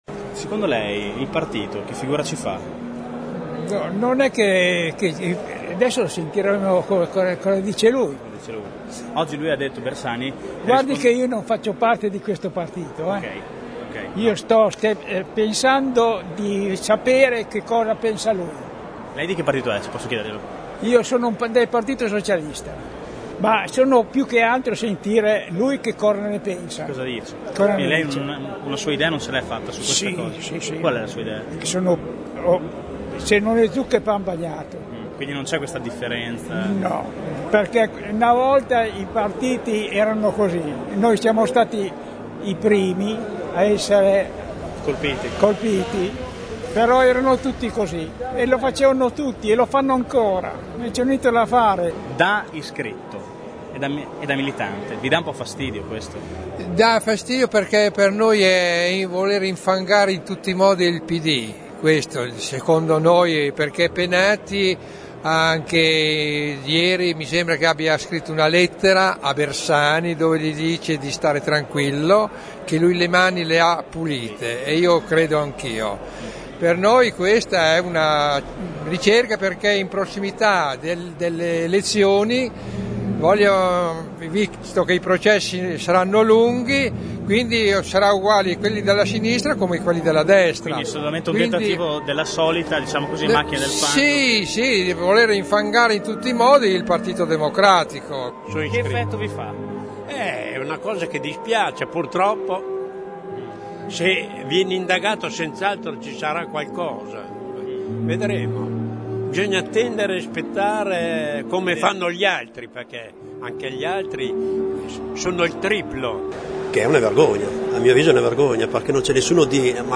voci-cittadini.mp3